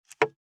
553魚切る,肉切りナイフ,
効果音